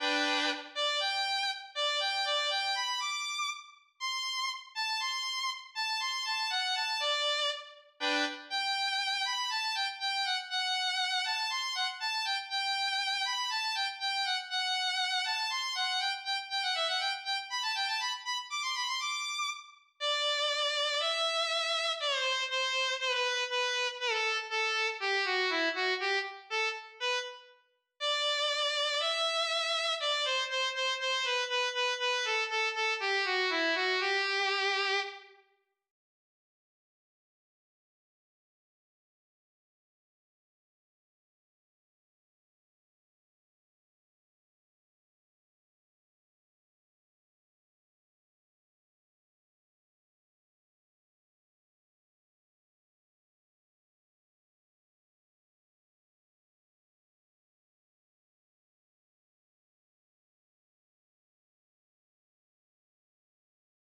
eine-kleine-viol-new.wav